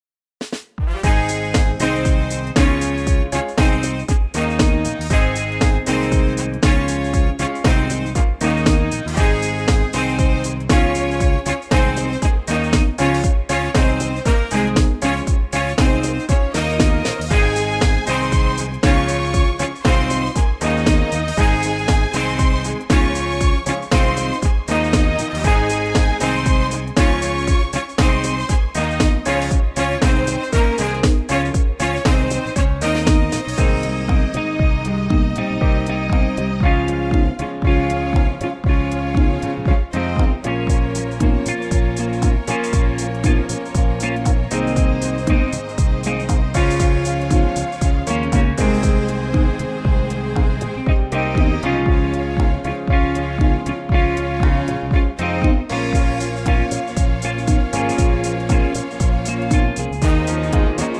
key - Ab - vocal range - Ab to Db (optional F top note)